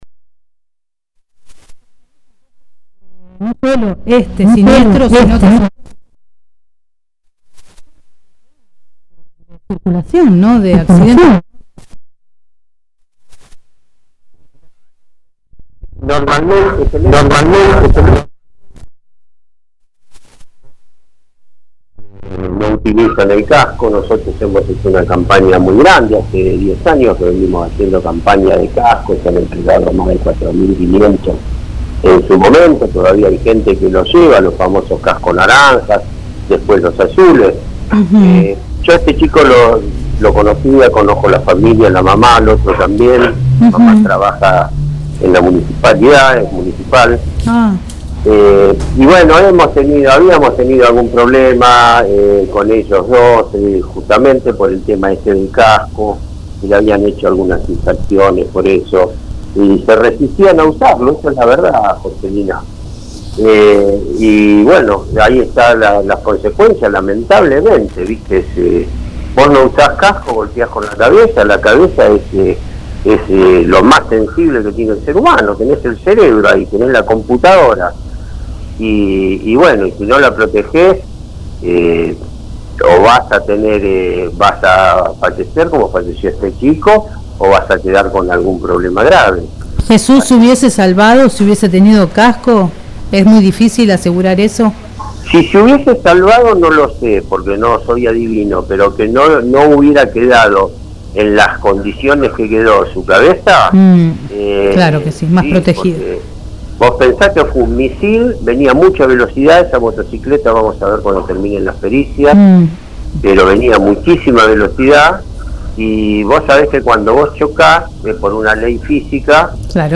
En conversaciones con el director de Tránsito municipal sobre este hecho, Pablo Jordán detalló que conocía al joven fallecido y a su familia, como también a quien lo acompañaba en la moto y está gravemente herido que es hijo de una trabajadora de la municipalidad.